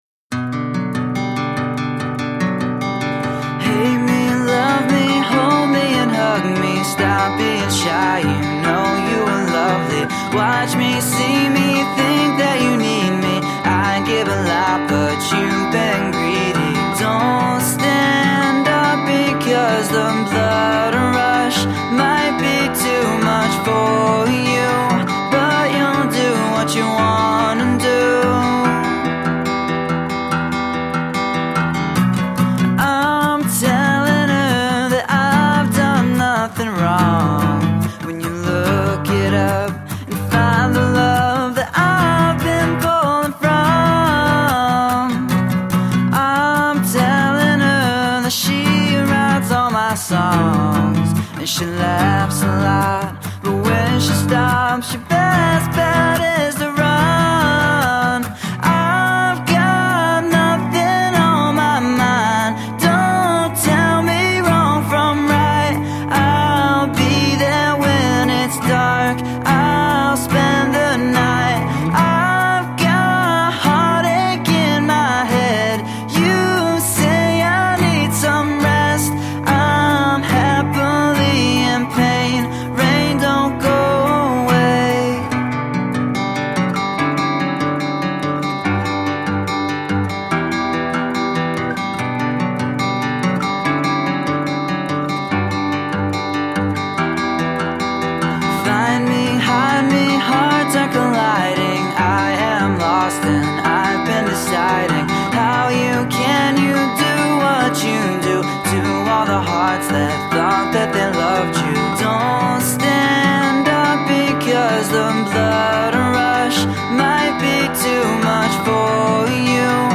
Guitar driven alternative rock.
Tagged as: Alt Rock, Rock, Folk-Rock, Indie Rock